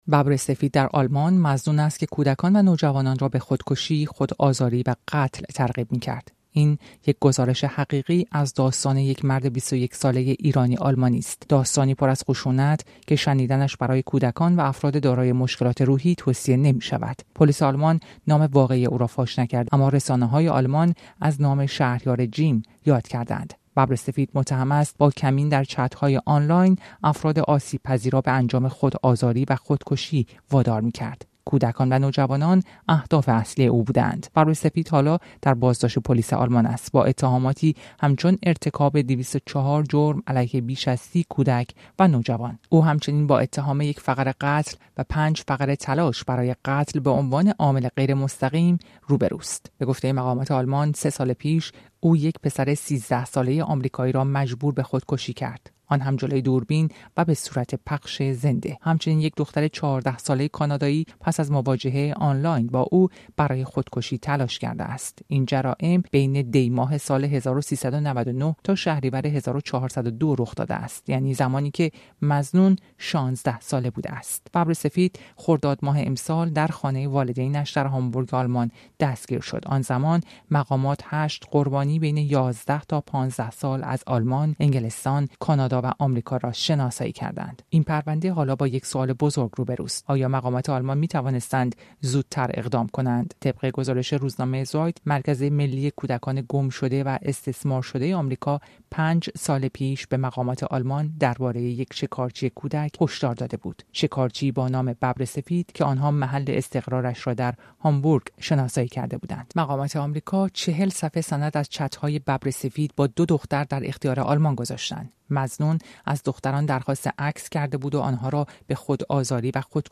او متهم است که در فضای مجازی، کودکان و نوجوانان را به خشونت ترغیب می‌کرده است. این گزارش رادیوی حاوی اطلاعات خشونت‌آمیز است که شنیدنش برای همه، مخصوصاً کودکان، مناسب نیست.